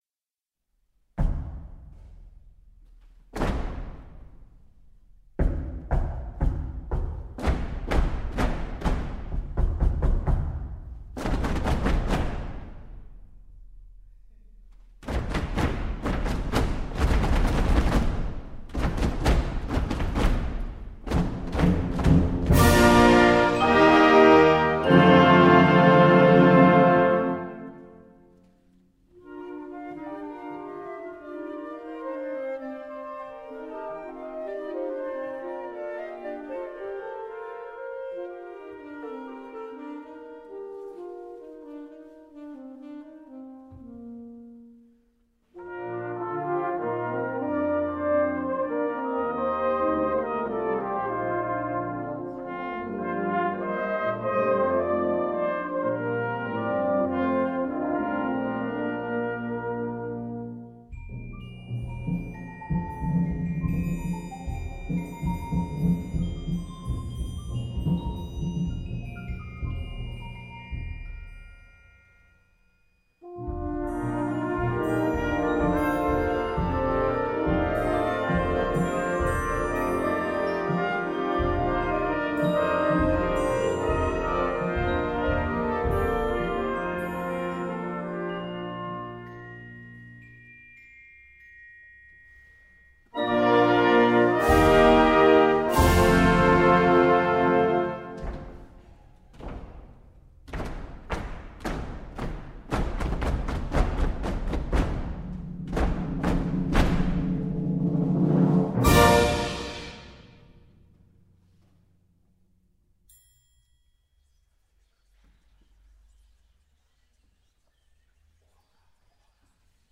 Gattung: “Improvisations-Suite” für Jugendblasorchester
Besetzung: Blasorchester